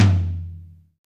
• Hi Tom Single Hit F Key 12.wav
Royality free tom sound tuned to the F note. Loudest frequency: 691Hz
hi-tom-single-hit-f-key-12-jZ7.wav